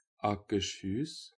Akershus (Norwegian pronunciation: [ɑkəʂˈhʉːs]
Akershus.ogg.mp3